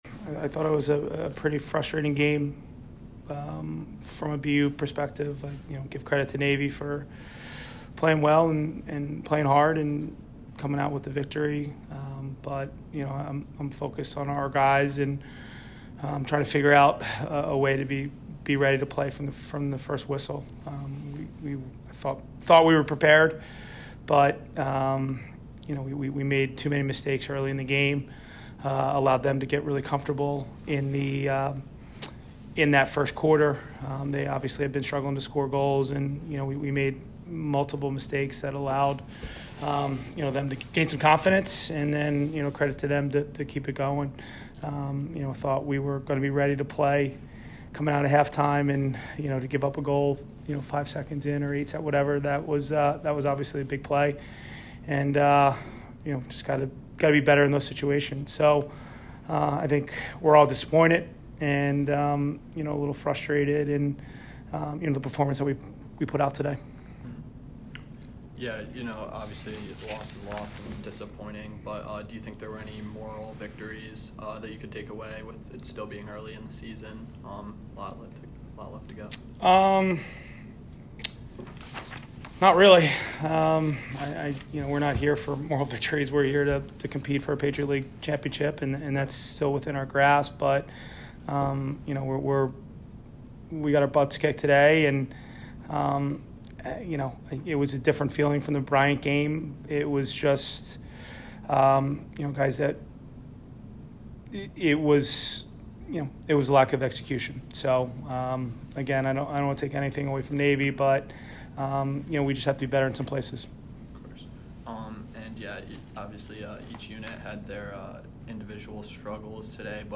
Navy Postgame Interview